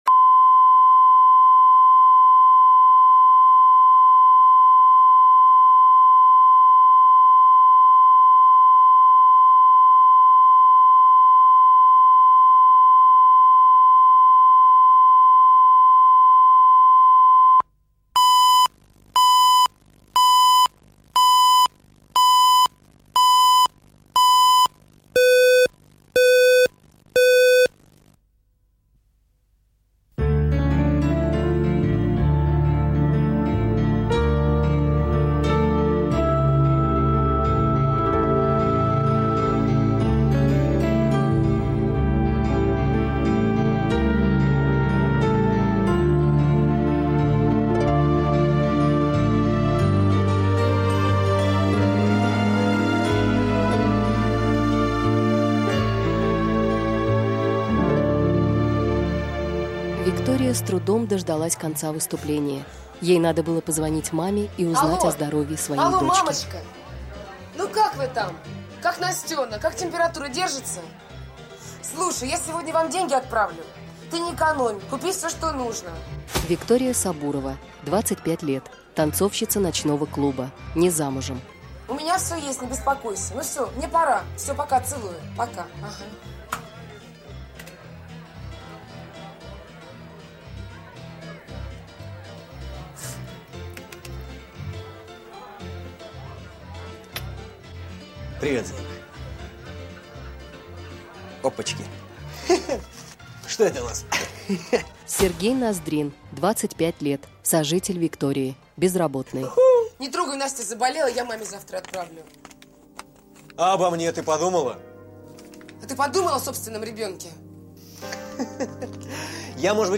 Аудиокнига Дамский танец | Библиотека аудиокниг
Прослушать и бесплатно скачать фрагмент аудиокниги